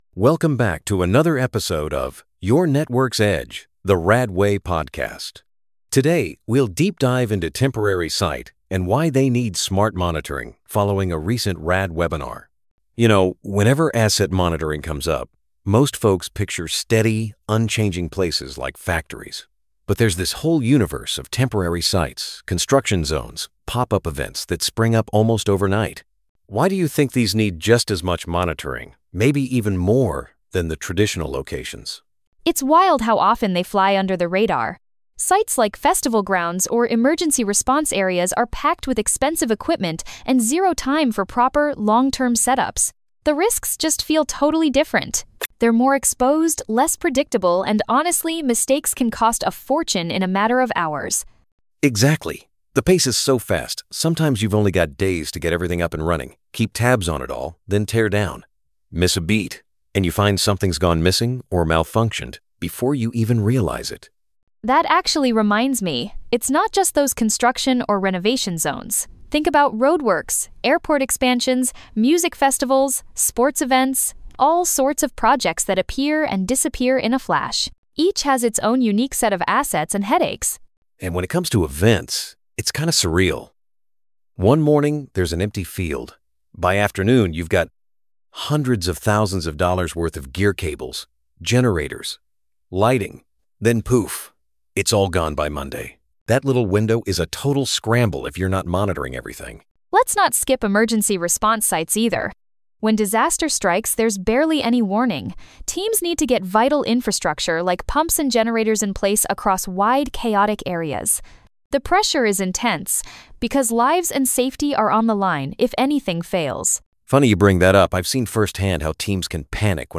NoteGPT_AI_Podcast_Smart-IoT-Solutions-for-Temporary-Site-Monitoring.mp3